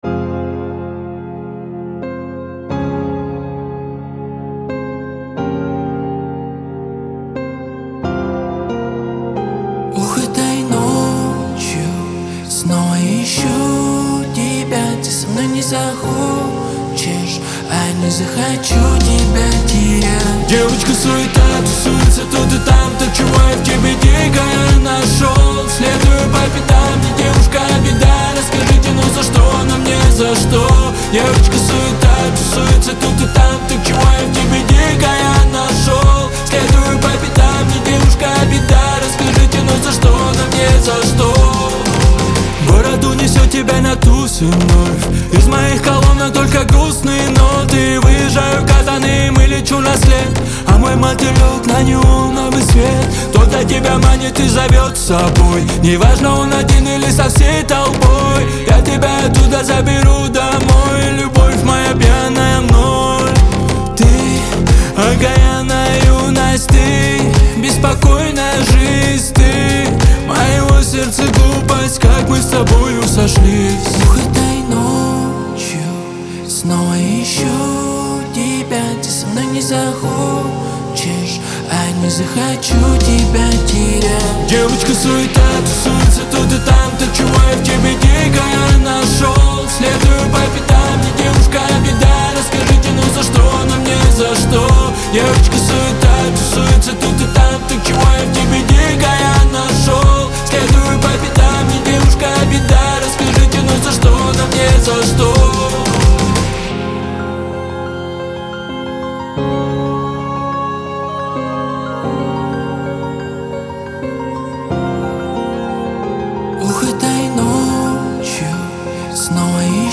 Лирика
Кавказ – поп